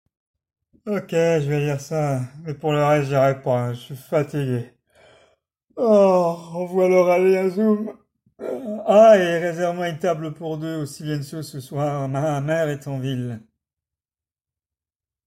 Voix off marionnette